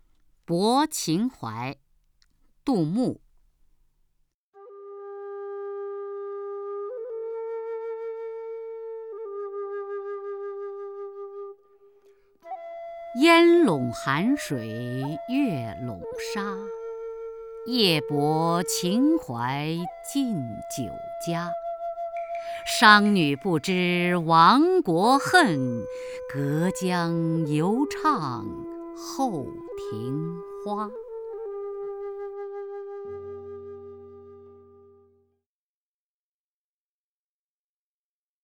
雅坤朗诵：《泊秦淮》(（唐）杜牧) (右击另存下载) 烟笼寒水月笼沙， 夜泊秦淮近酒家。